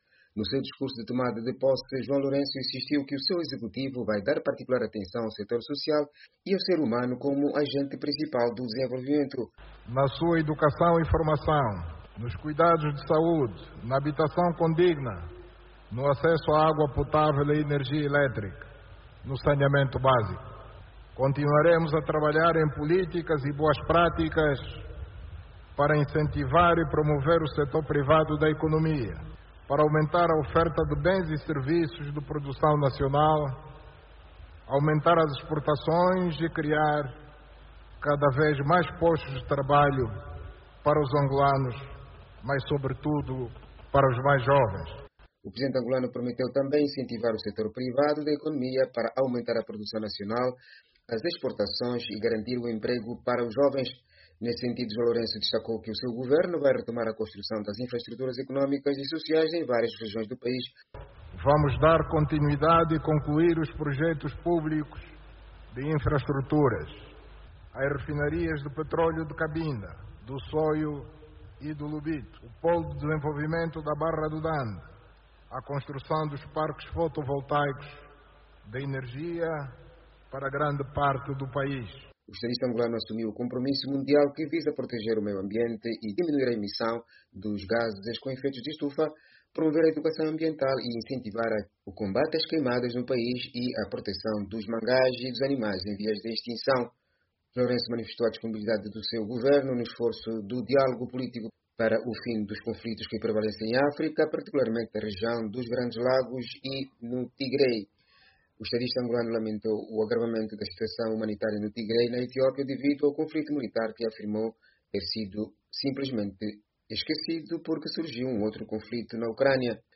O discurso de João Lourenço - 3:13